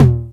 TR909TOM2.wav